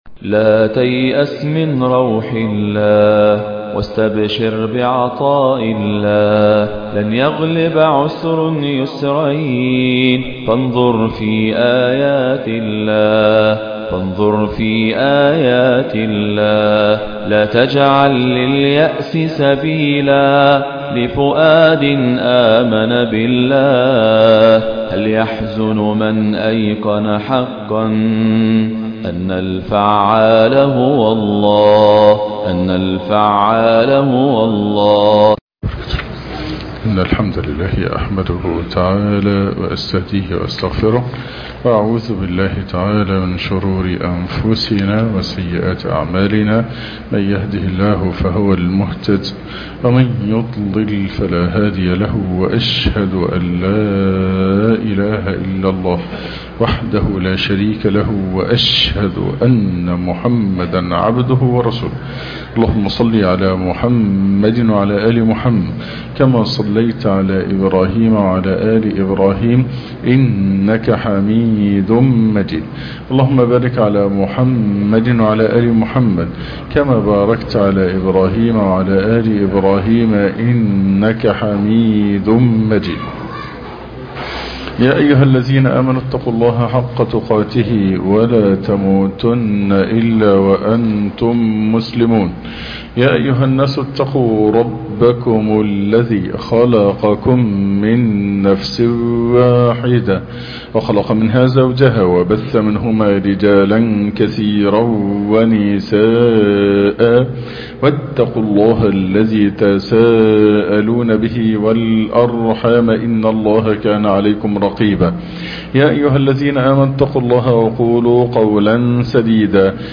الطريق إلى القلب السليم - الدرس الخامس عشر